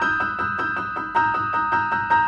Index of /musicradar/gangster-sting-samples/105bpm Loops
GS_Piano_105-E1.wav